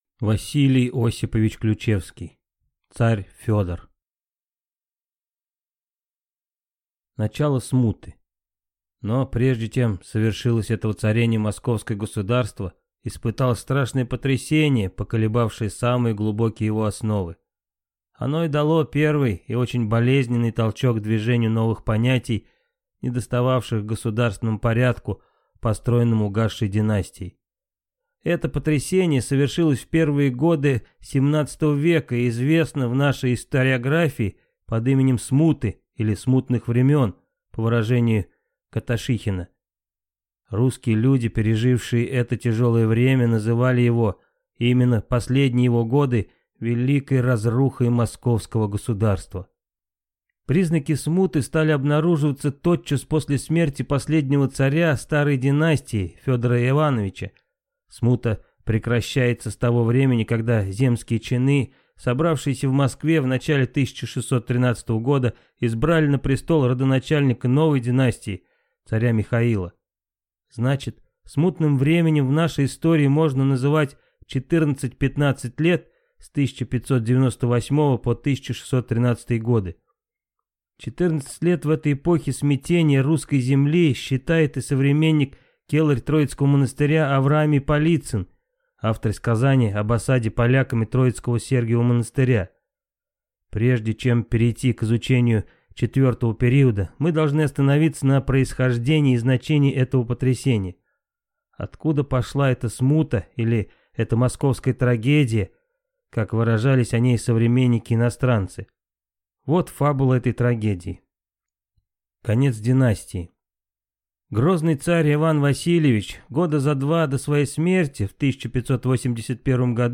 Аудиокнига Царь Федор | Библиотека аудиокниг